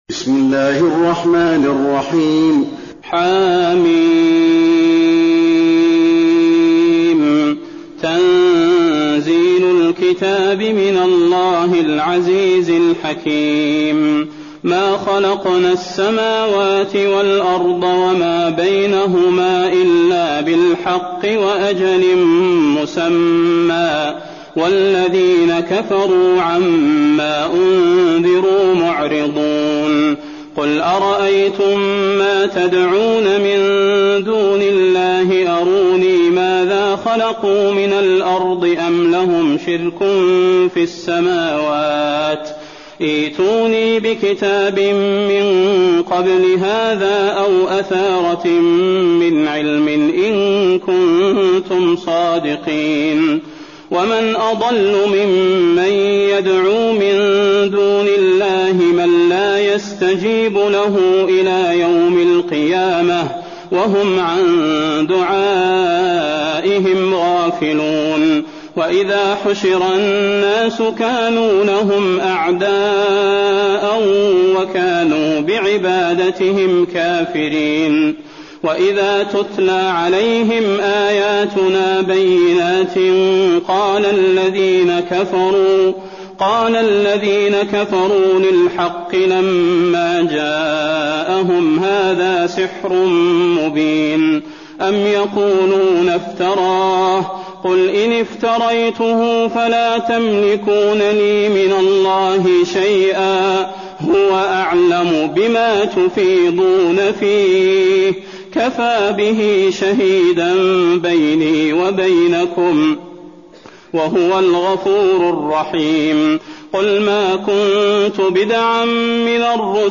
المكان: المسجد النبوي الأحقاف